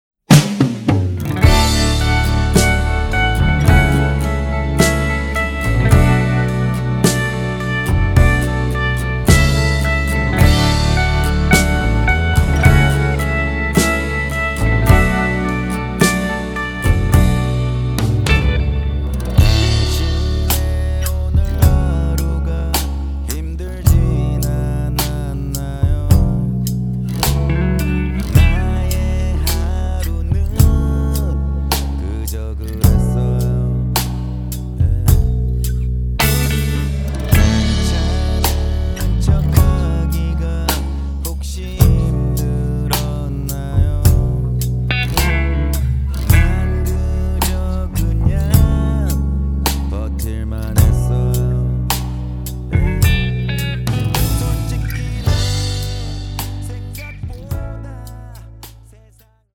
음정 원키 4:35
장르 가요 구분 Voice Cut